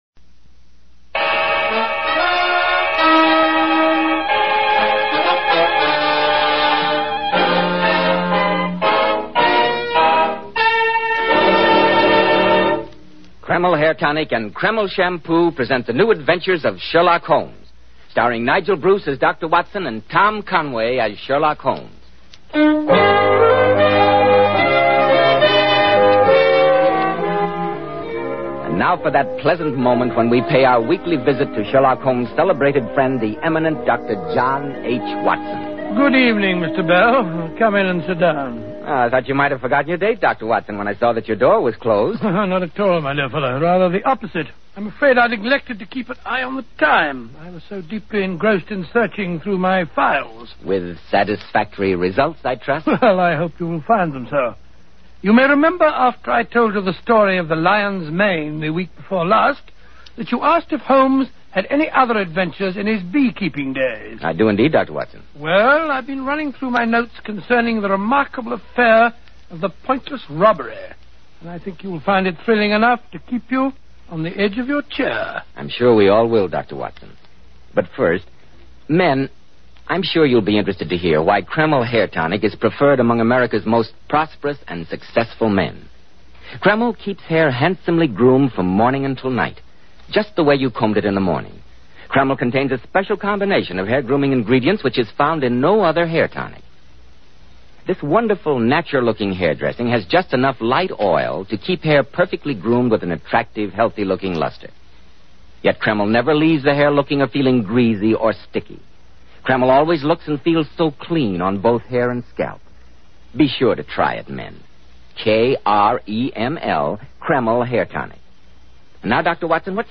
Radio Show Drama with Sherlock Holmes - The Pointless Robbery 1947